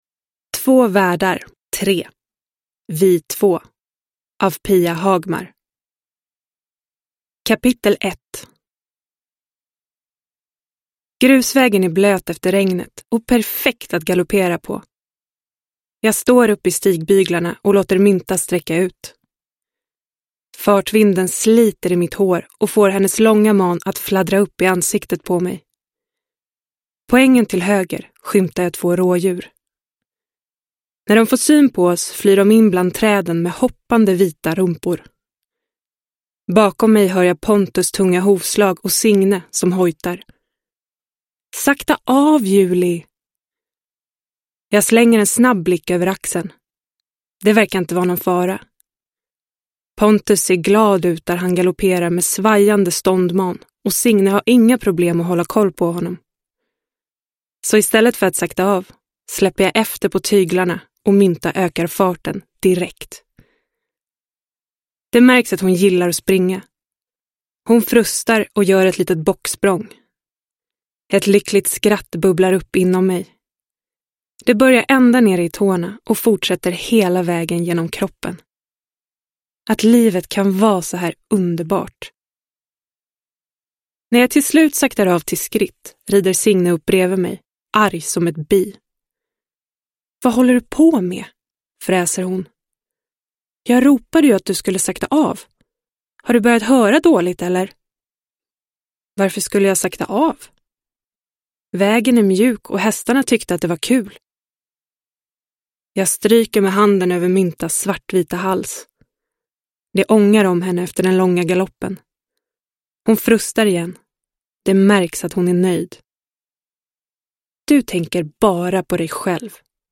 Vi två – Ljudbok – Laddas ner